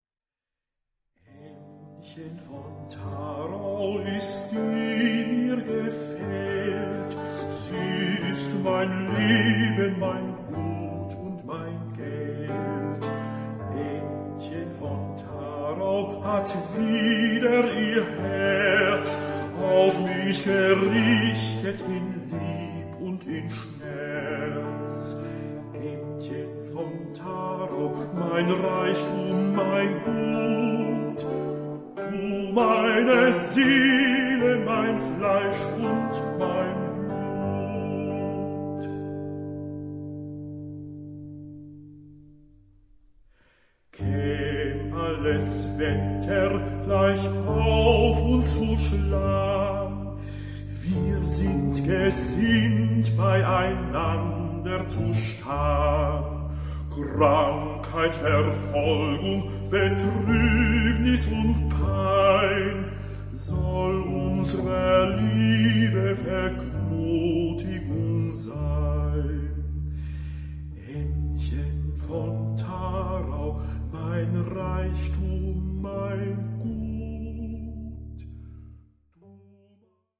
ALTE VOLKSLIEDER
Die Lieder werden schlicht von der Gitarre begleitet.